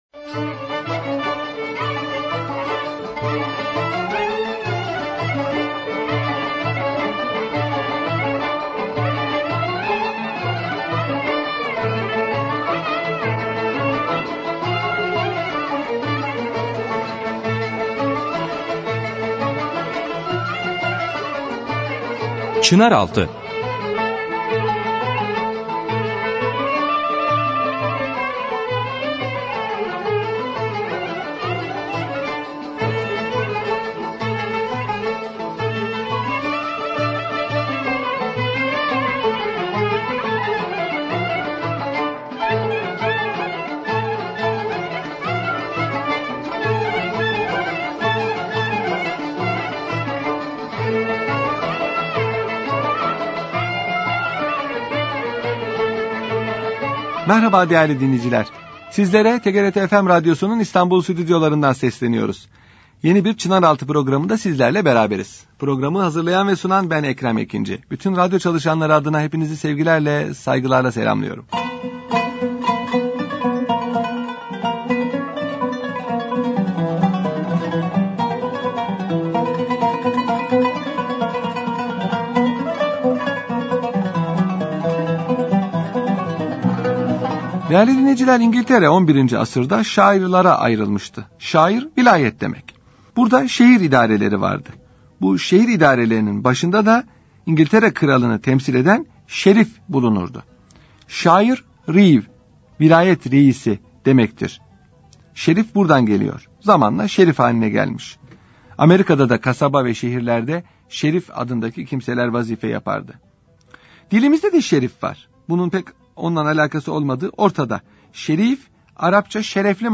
Radyo Programi - Şerif Hüseyin Paşa - Osmanlı Bankacılığı- Osmanlı Harb Hukuku